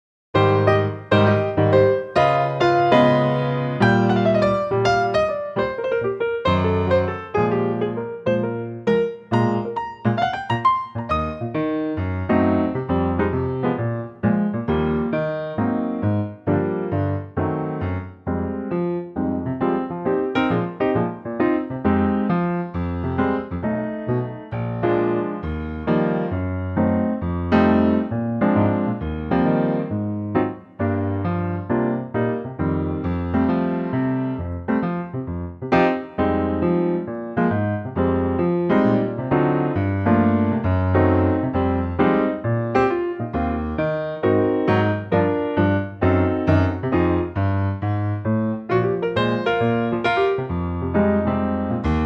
Unique Backing Tracks
key - Eb - vocal range - C to Eb
The old standard in a piano only arrangement.